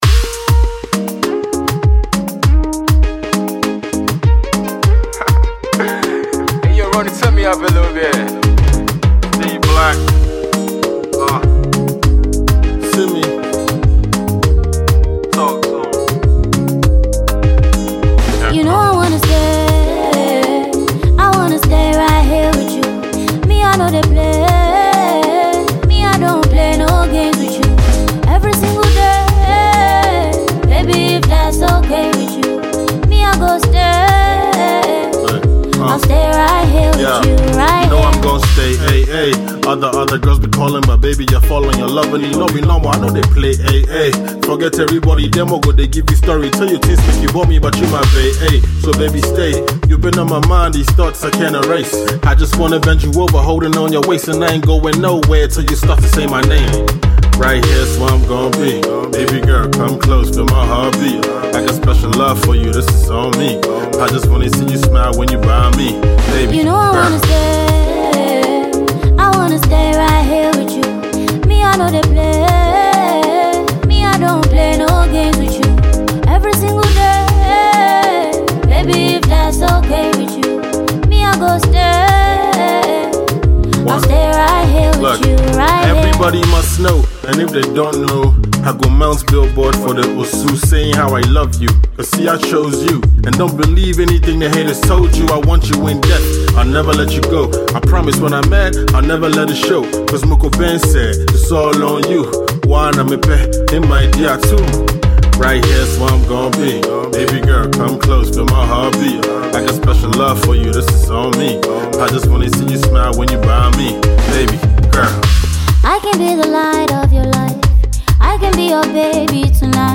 afrobeats